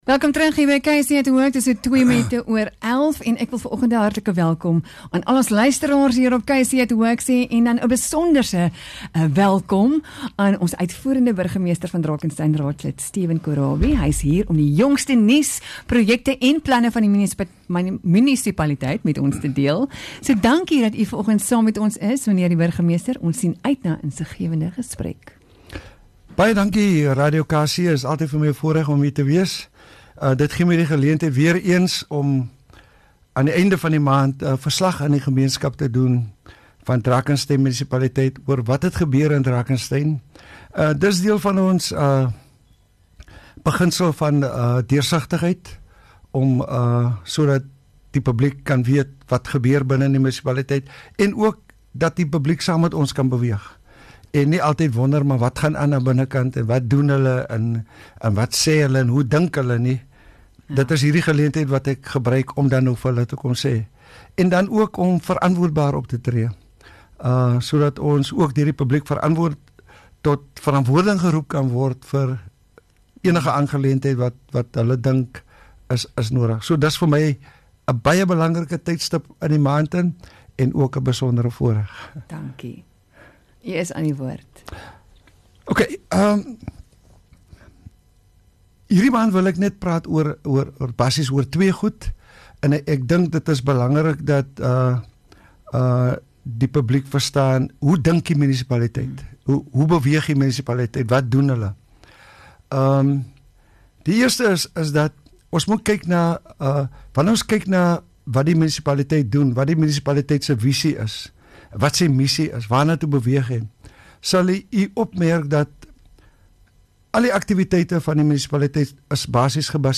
30 Jun Mayor Hour with Drakenstein Executive Mayor Cllr Stephen Korabie